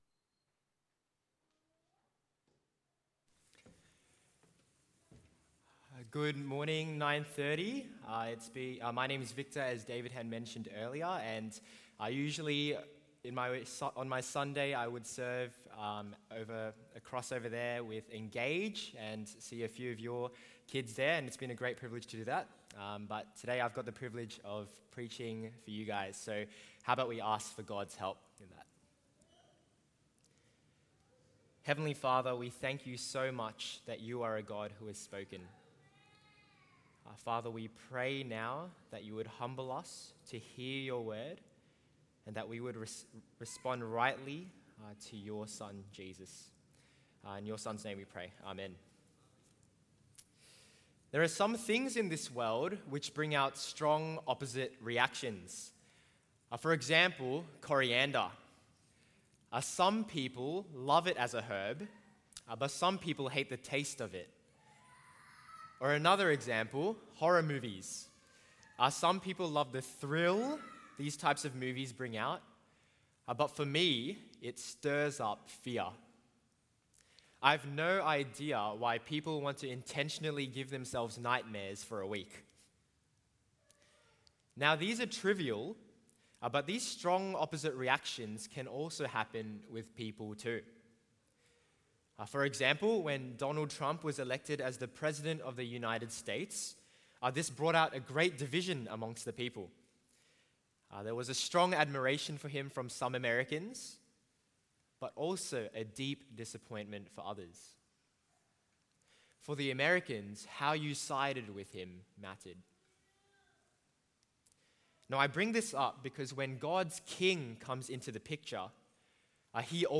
1 Samuel 18:1-16 Sunday sermon